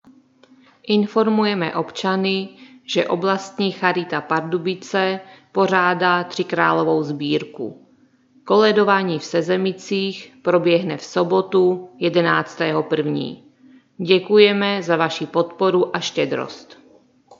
Hlášení městského rozhlasu 10. a 11.01. – 13. a 14.01.2025